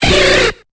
Cri de Caratroc dans Pokémon Épée et Bouclier.